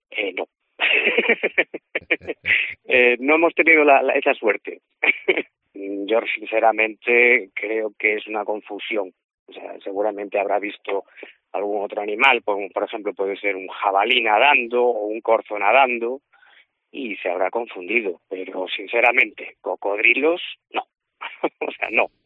El testimonio de un pescador de Galicia tras el "avistamiento" de un cocodrilo en el río